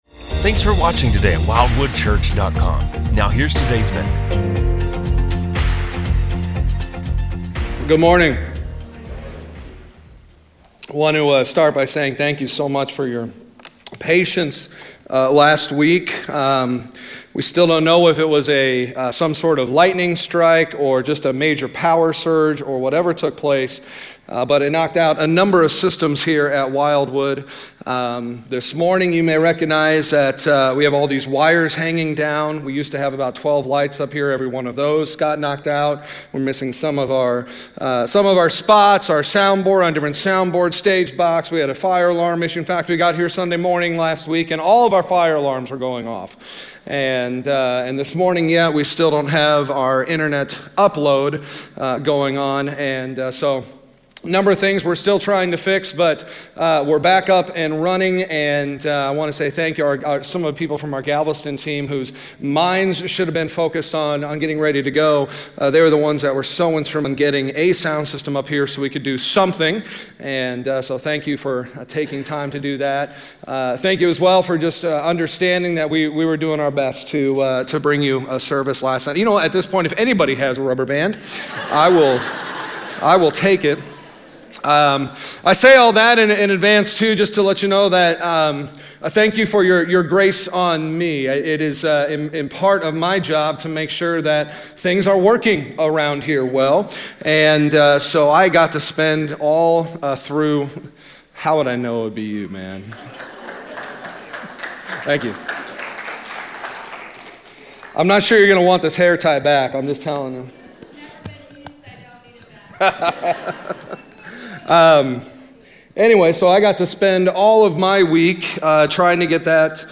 A message from the series "Wisdom From Above."